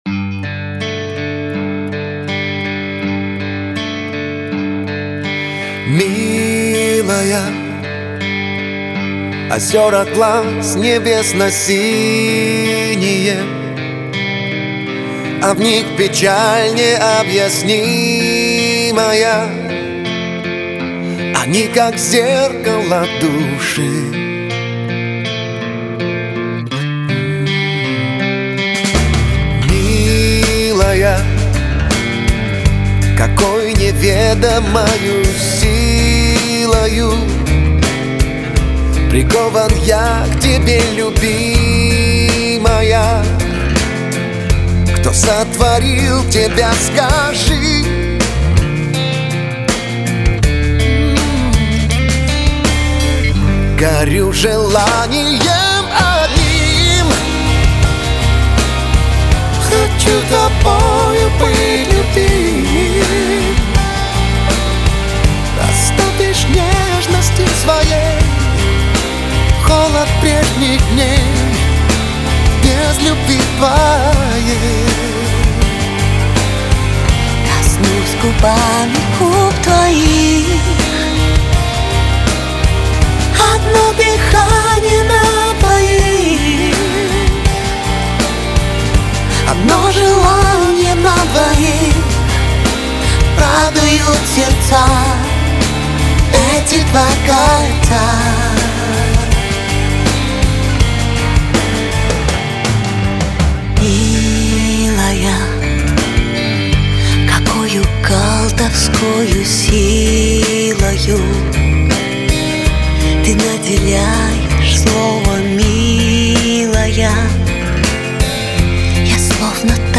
Категория: Pop